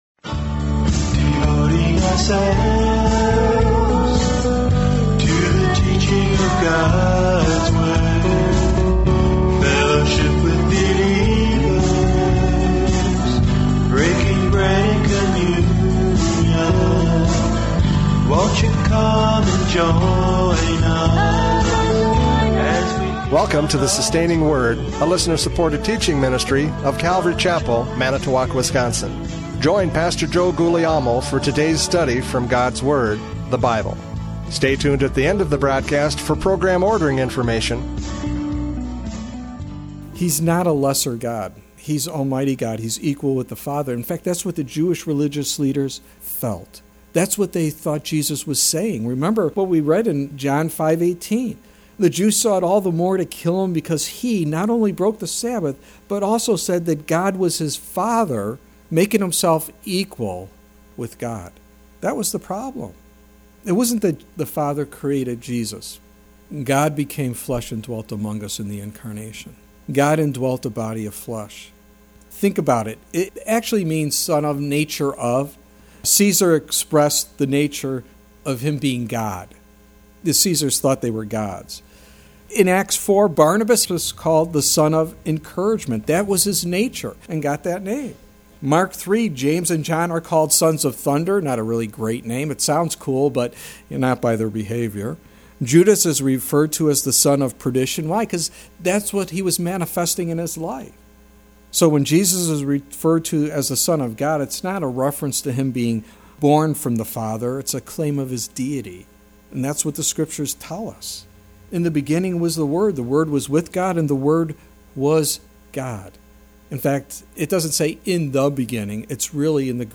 John 5:37-38 Service Type: Radio Programs « John 5:37-38 Testimony of the Father!